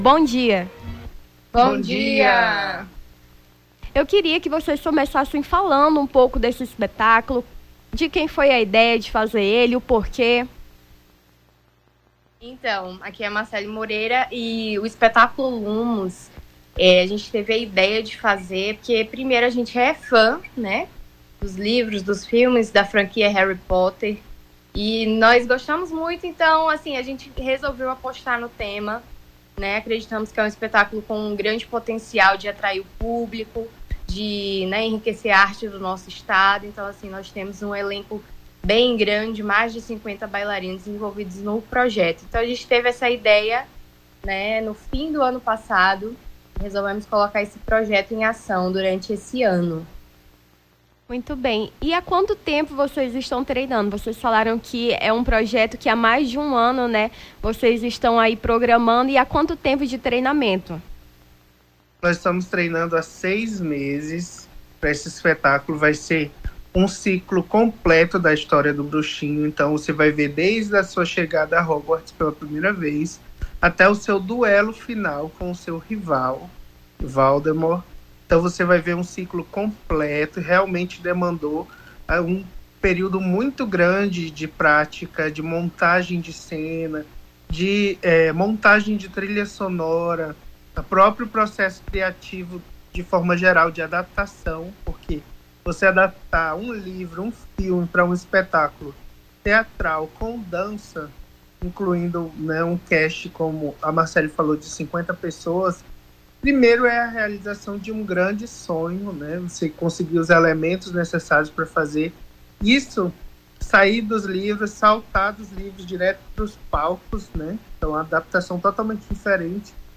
Nome do Artista - CENSURA - ENTREVISTA (ESPETACULO HARRY POTTER) 12-12-23.mp3